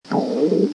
Download Stomach Growl sound effect for free.
Stomach Growl